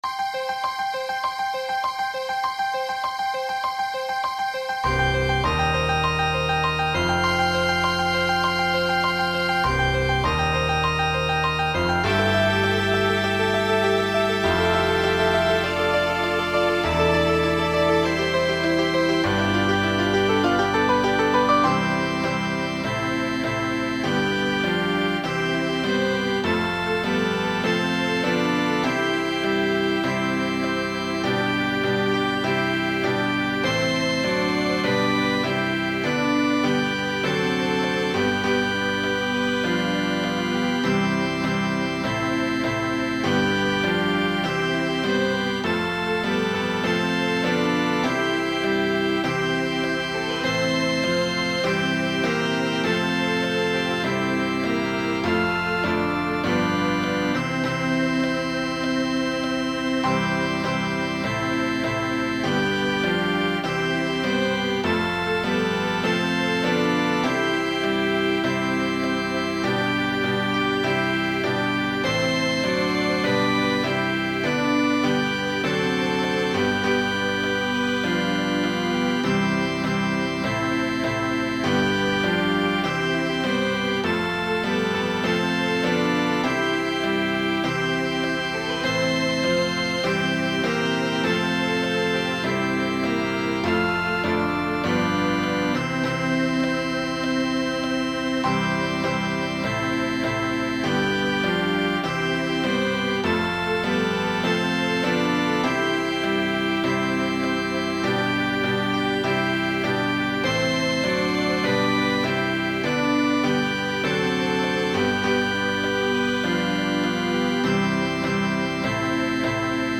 Holy” for organ, piano, violin 1, violin 2, and double bass.
Keep in mind this is just exported from Sibelius, so it’s really just for reference only.